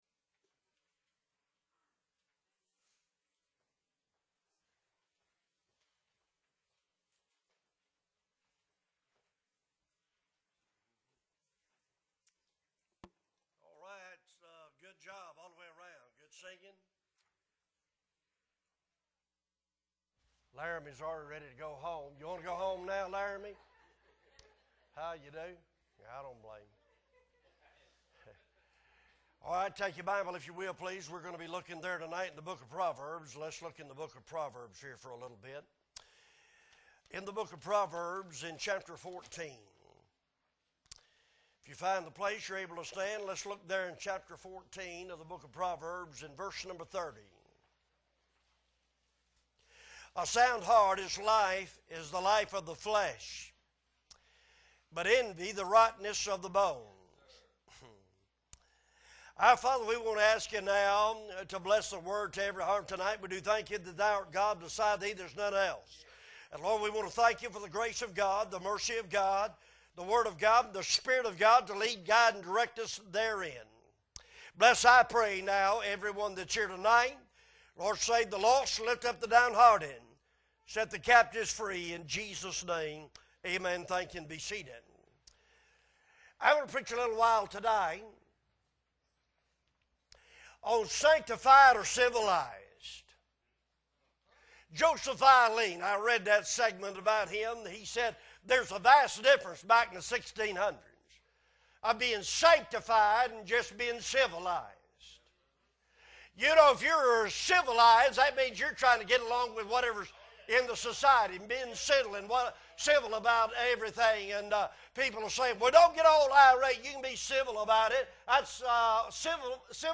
Wednesday service 9/28/2022 - Appleby Baptist Church